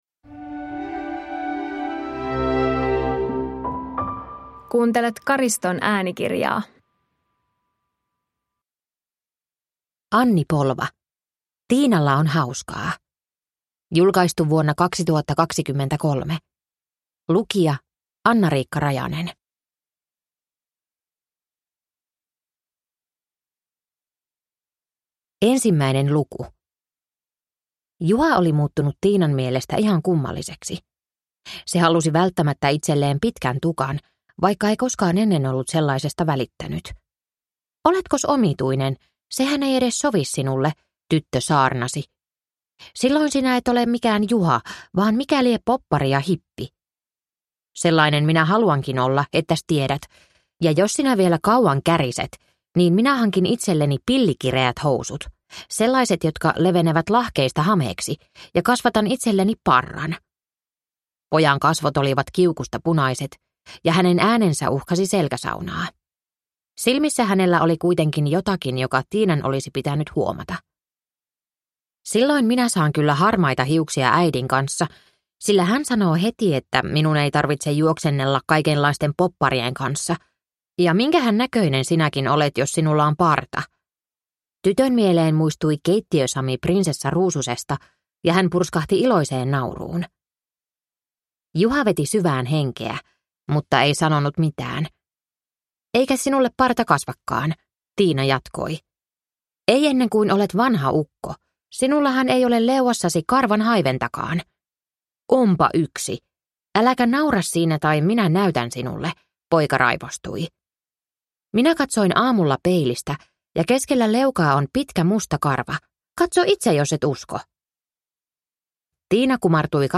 Tiinalla on hauskaa – Ljudbok